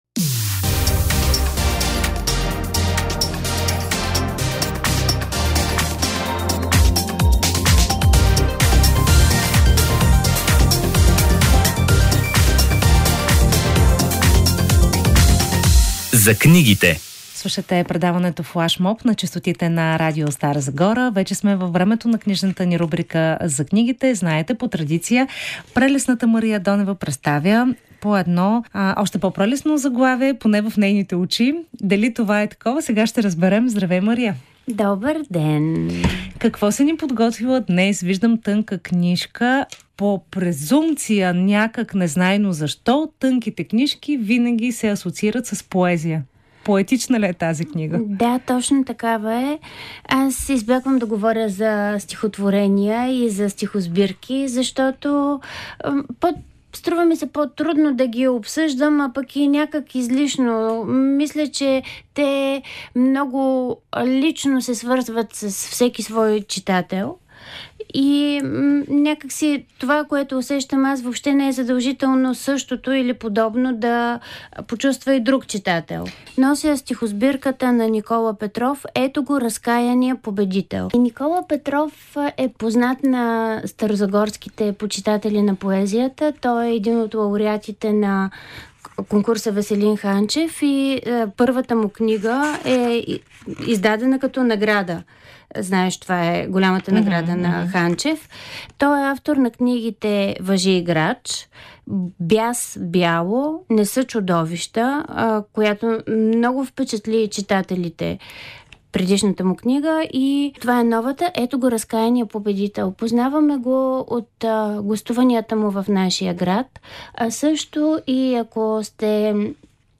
Тя е част от предаването „Флашмоб“, което се излъчва всяка събота от 16 часа по Радио Стара Загора.